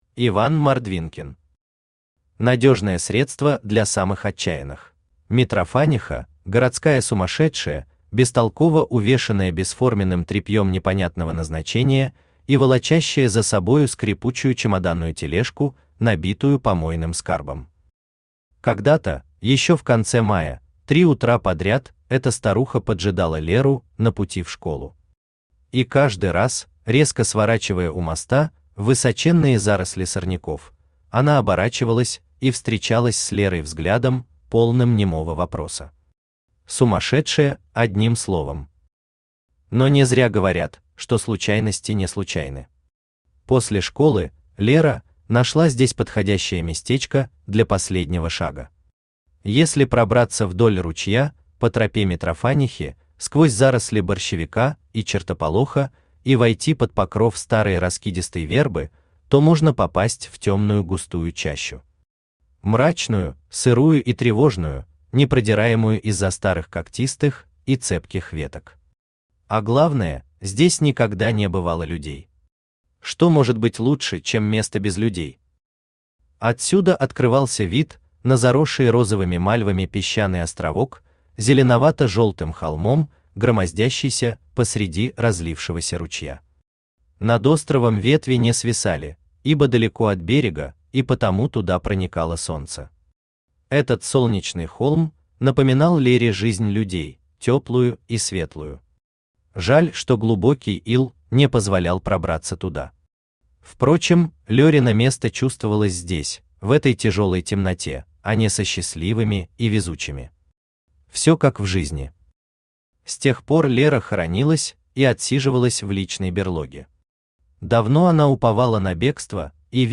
Аудиокнига Надежное средство для самых отчаянных | Библиотека аудиокниг
Aудиокнига Надежное средство для самых отчаянных Автор Иван Александрович Мордвинкин Читает аудиокнигу Авточтец ЛитРес.